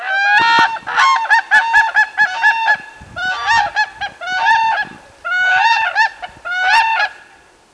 Whooping crane bugling
Click on the following to hear a whooping crane unison call:
Unison (334kb wave)
Unison.wav